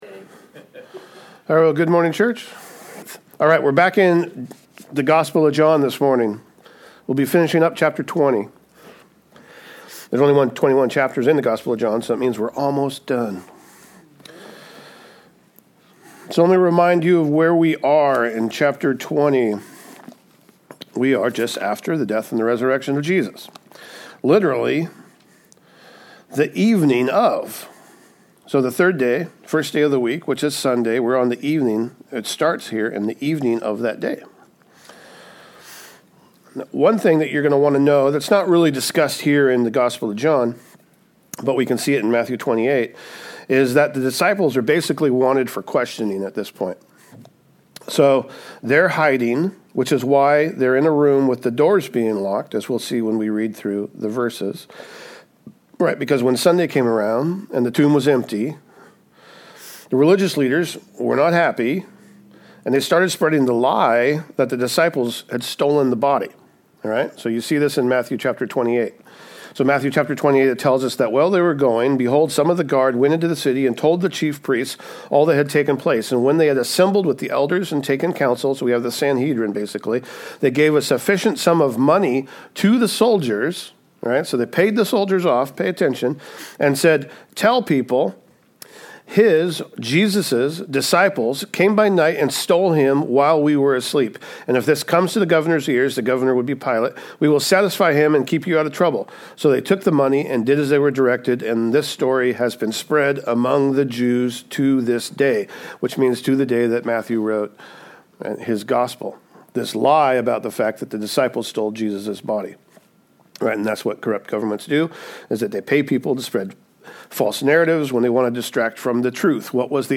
Sermons | Calvary Chapel Snohomish | Snohomish, Wa